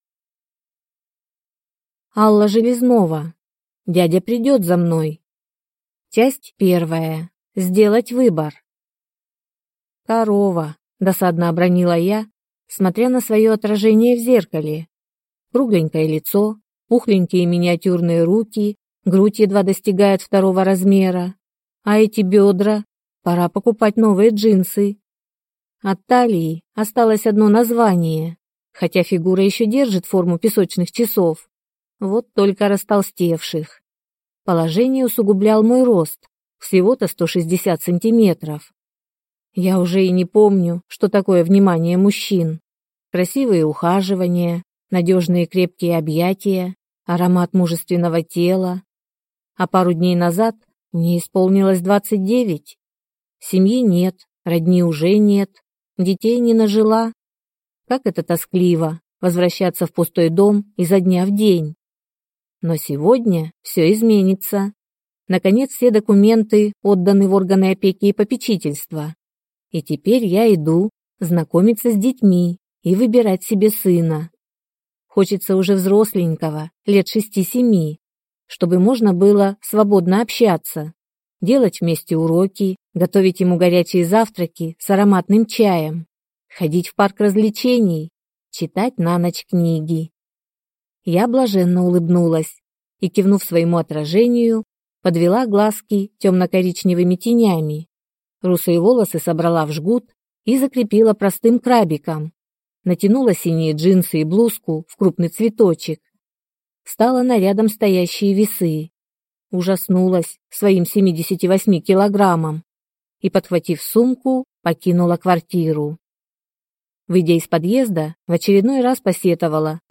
Аудиокнига Дядя придет за мной | Библиотека аудиокниг
Прослушать и бесплатно скачать фрагмент аудиокниги